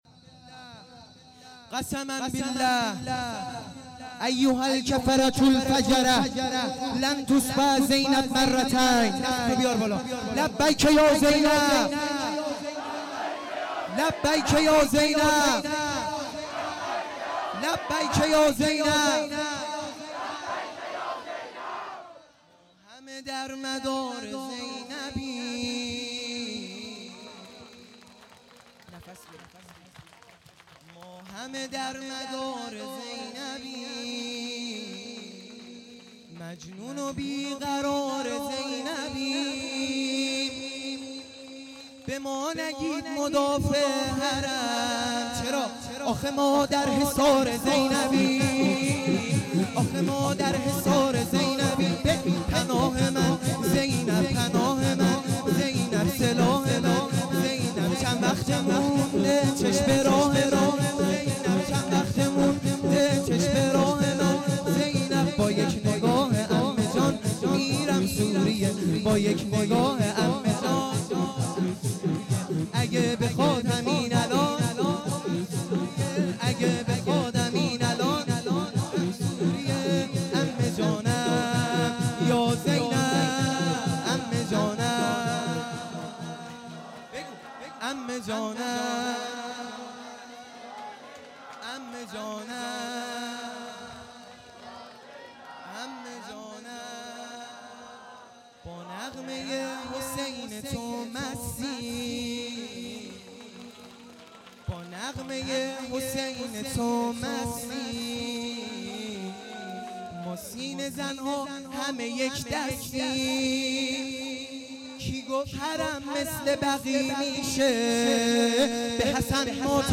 شور | مدافع حرم